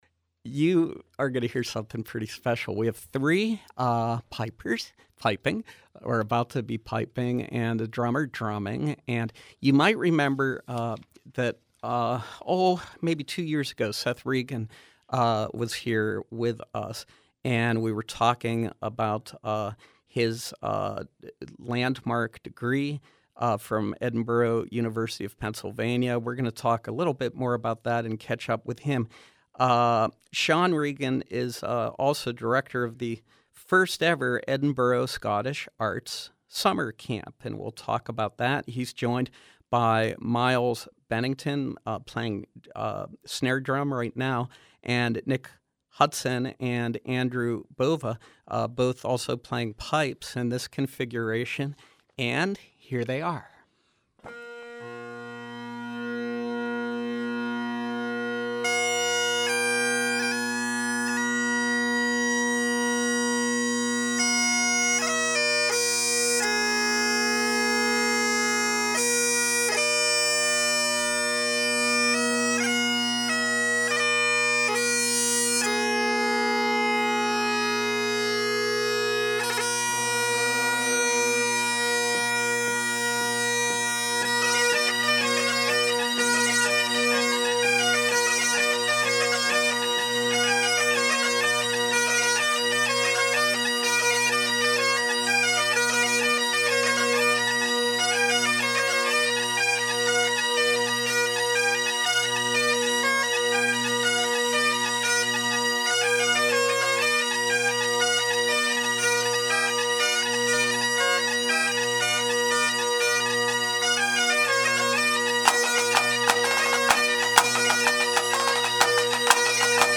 Live Music
percussionist
performing traditional Scottish pieces for bagpipes